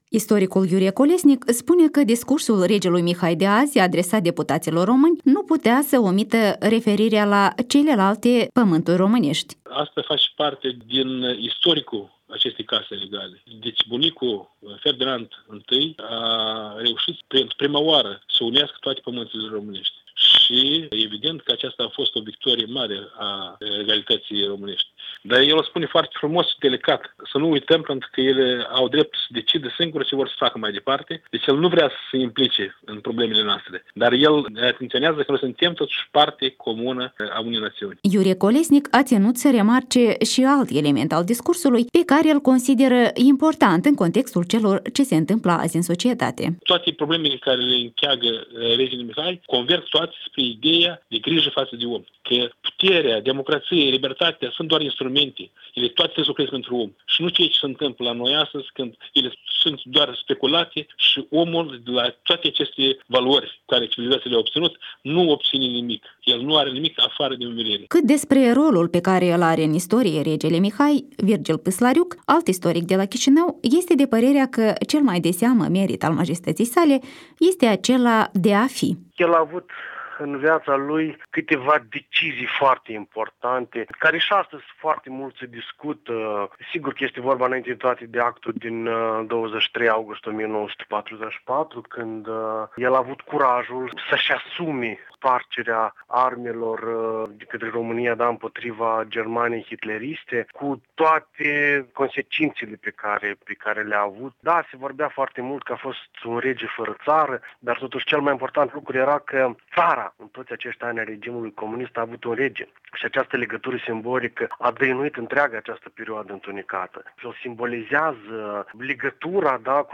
Trei istorici, trei opinii convergente despre rolul istoric al regelui Mihai